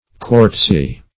Quartzy \Quartz"y\